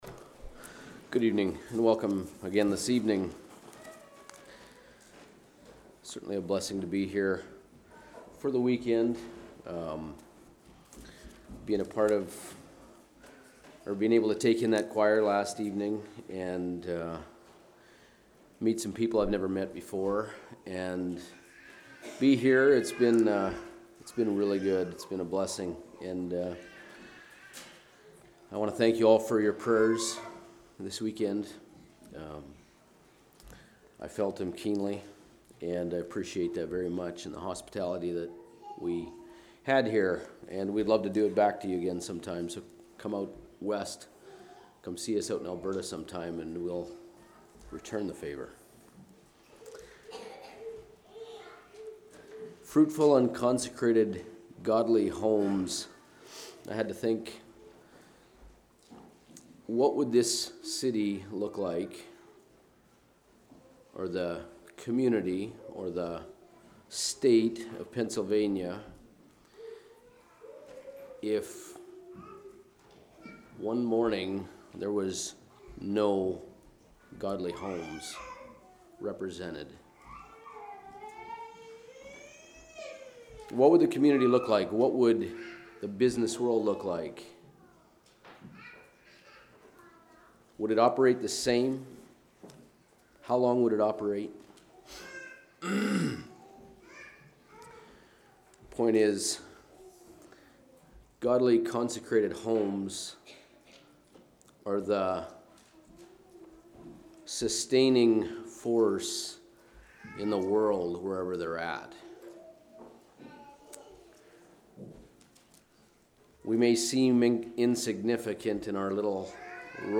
Sermons
Altoona | Bible Conference 2026